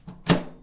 声音制造者" 关闭微波炉
描述：关闭微波炉的门得到的声音很吵。
声道立体声